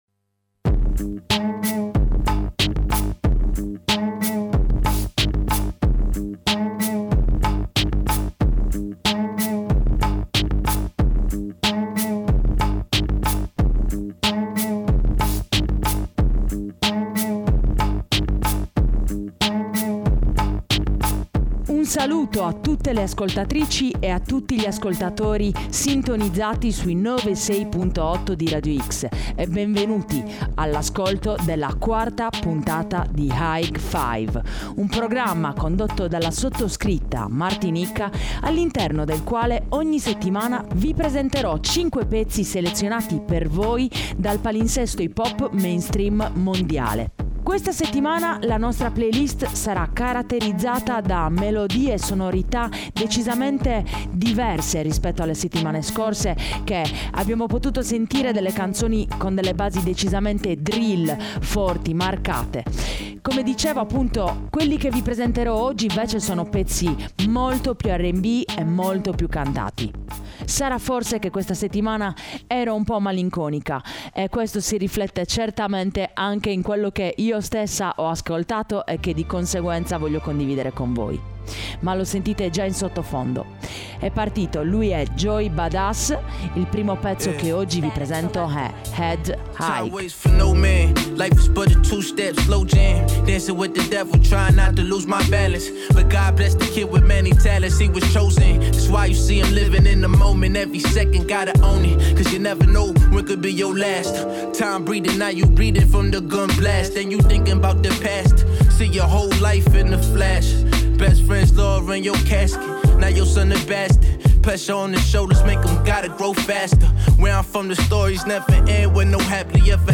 hip hop / rap / trap